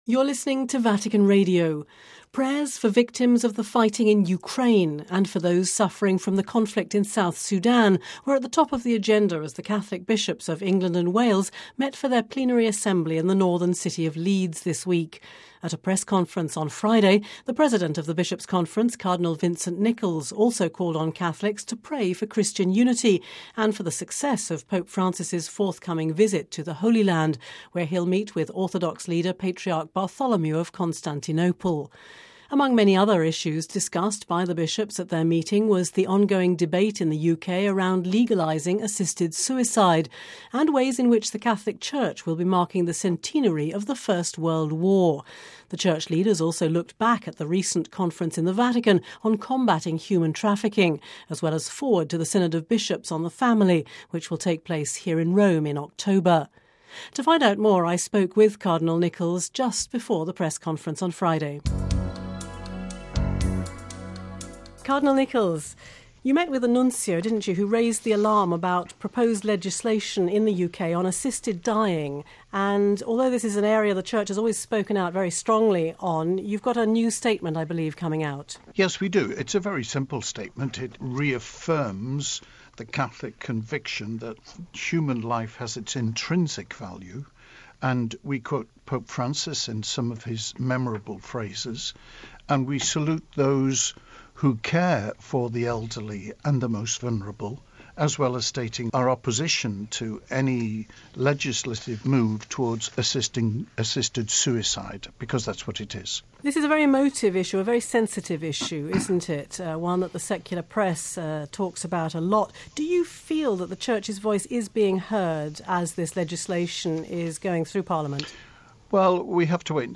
(Vatican Radio)